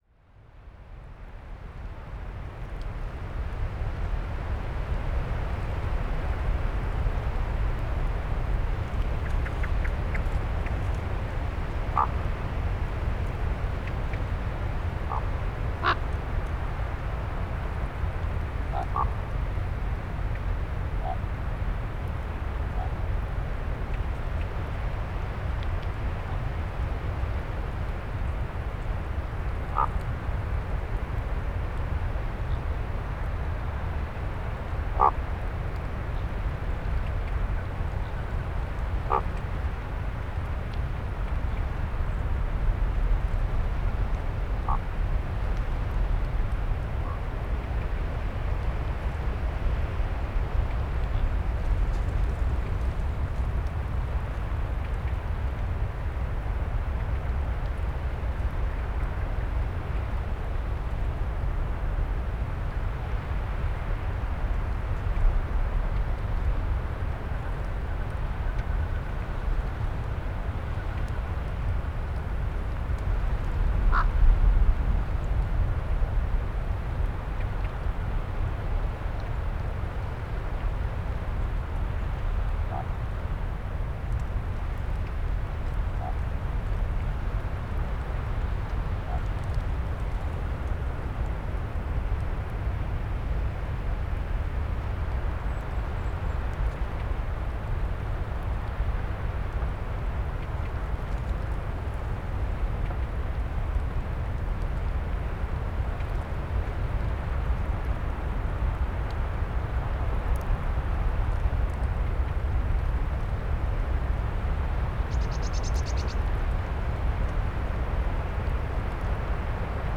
101222, Northern Raven Corvus corax, pair, display flight
Rode NT1A in a ORTF setup.